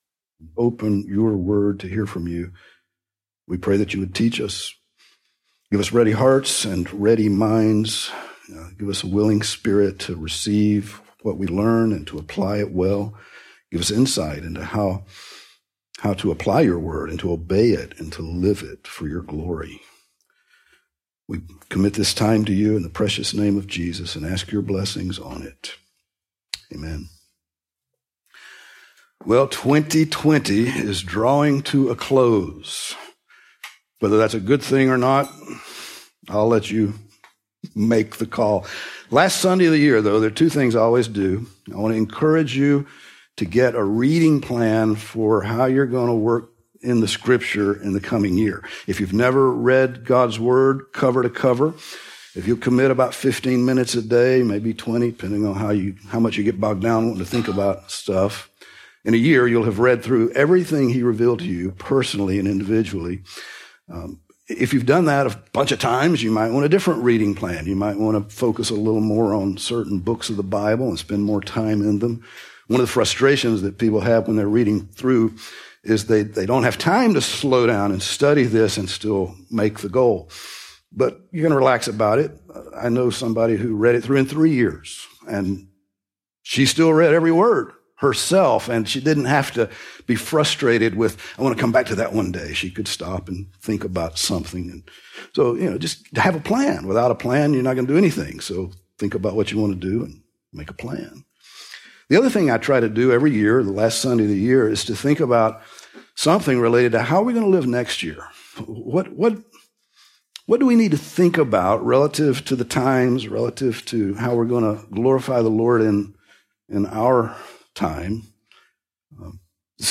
Grace Bible Church - Sermons